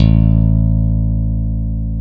Index of /90_sSampleCDs/Roland LCDP02 Guitar and Bass/BS _Rock Bass/BS _Chapmn Stick